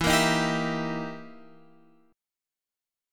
EmM7b5 chord {x x 2 3 4 3} chord